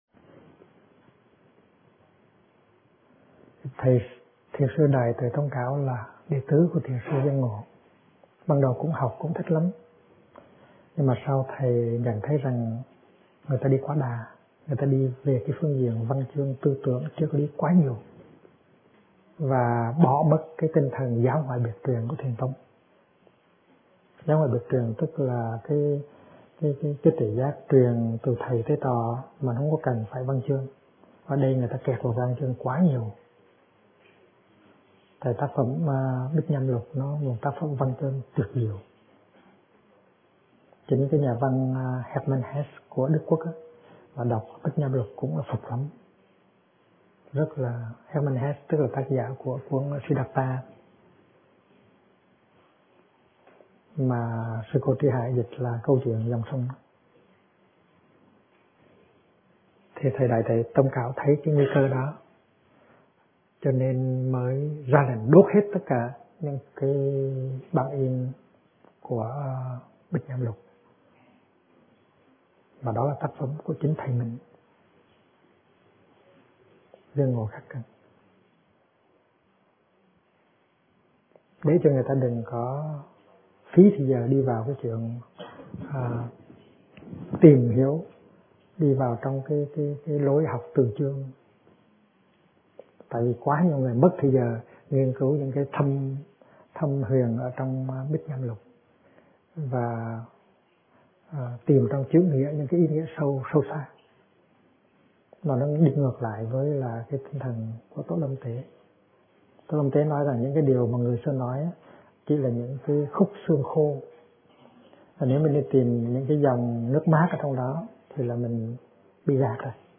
Kinh Giảng Ai Đang Đi Tìm Phật Pháp - Thích Nhất Hạnh